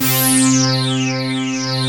BIG LEADC3-R.wav